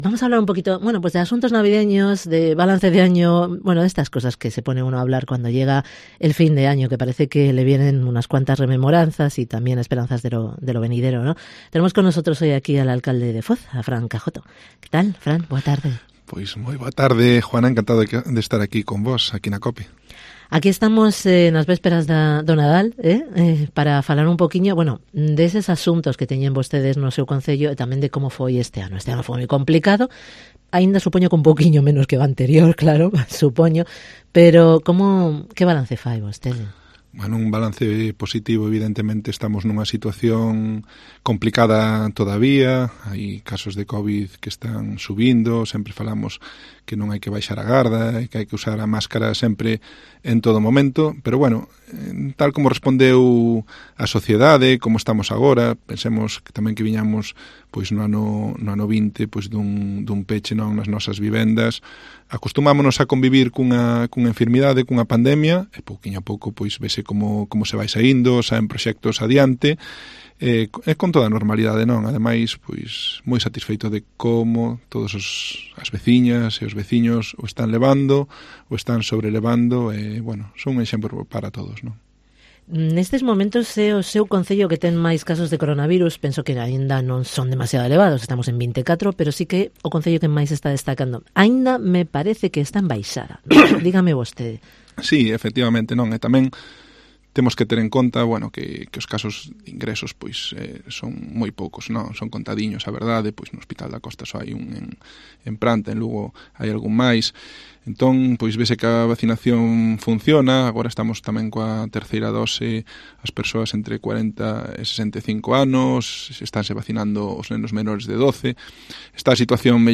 Entrevista con el alcalde de Foz, Fran Cajoto